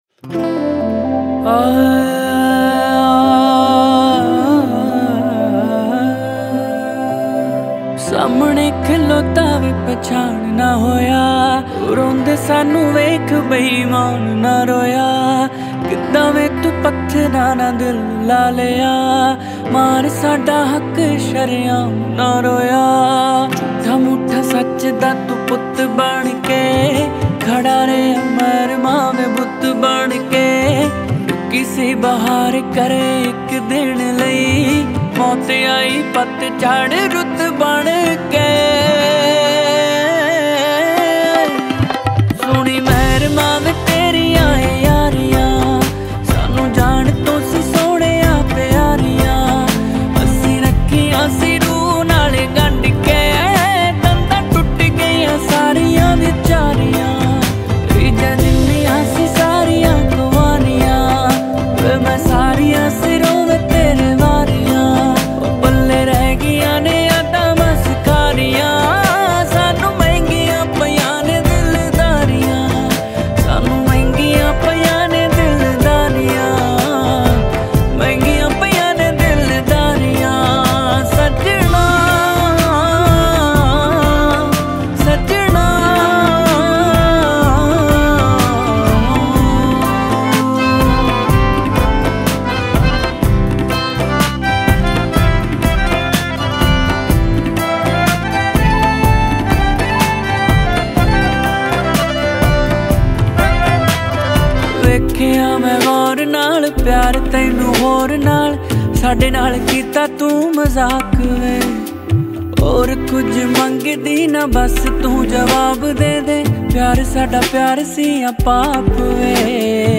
Song Genre : Latest Punjabi Songs